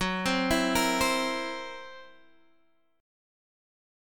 F#7b5 chord